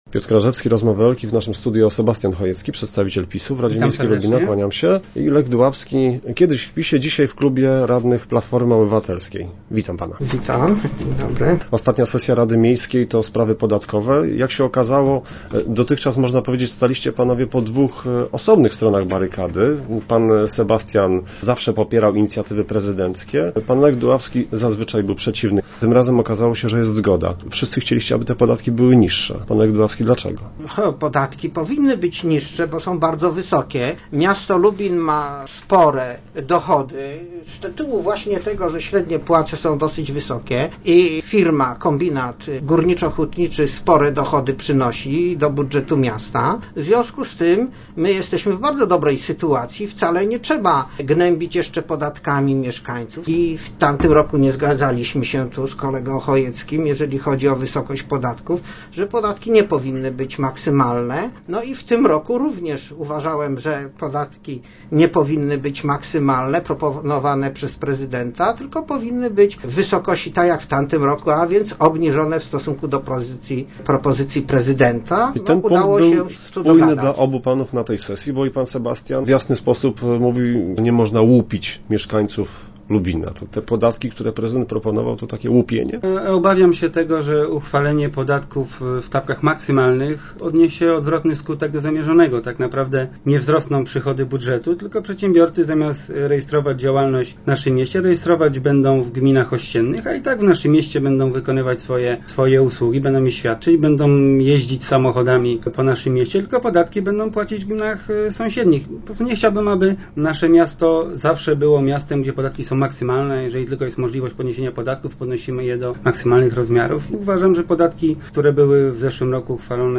Sebastian Chojecki (PiS) i Lech Duławski (PO) przekonywali, że podatków nie można podnosić W Rozmowach Elki opowiadali jak argumentowali swoje propozycje.